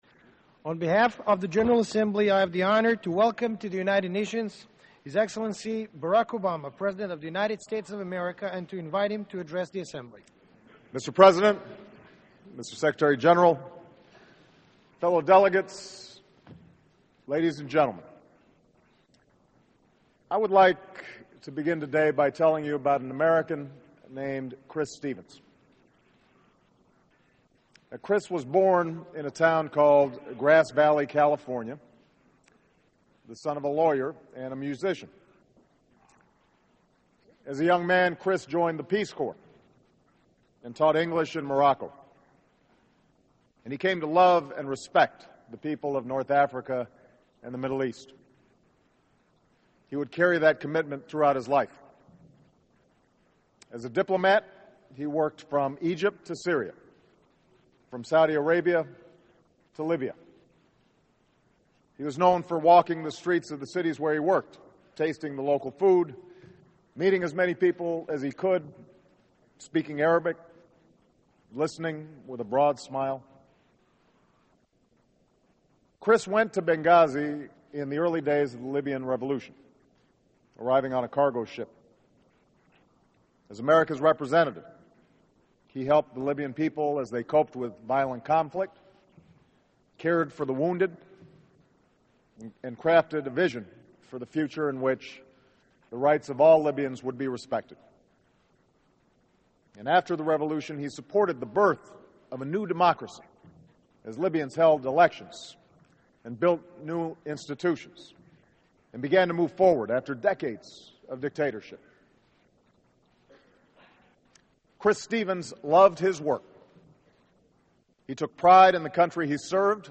Remarks by President Barak Obama on the Opening of the 67th UNGA; 25 September 2012; New York.Full
Full Speech, English.